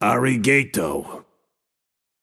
Shopkeeper voice line - Ari-gay-toh.